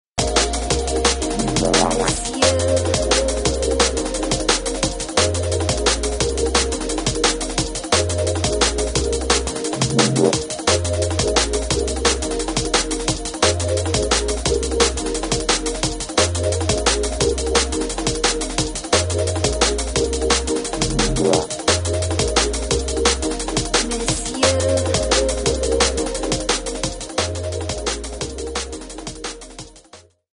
TOP > Deep / Liquid